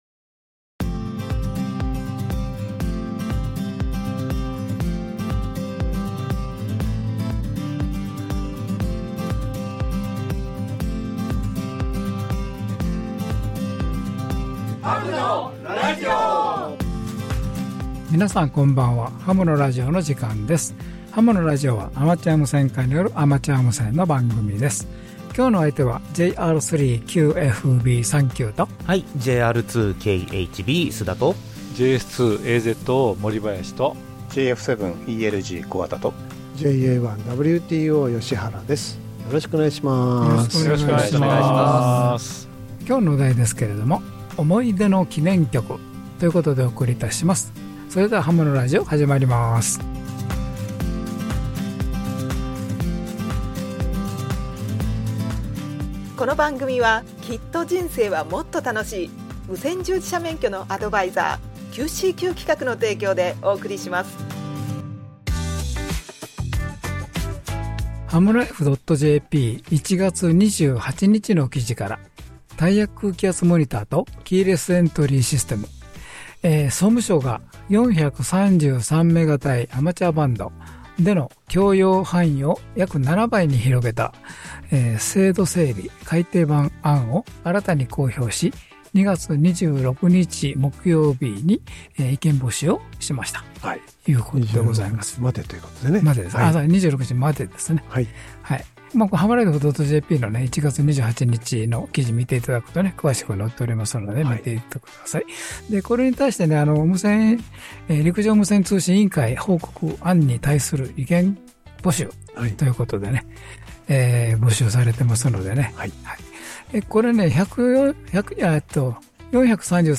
ハムのラジオ第684回の配信です。 (2026/2/8 ラジオ成田から放送)